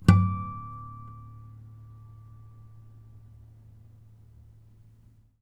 strings_harmonics
harmonic-05.wav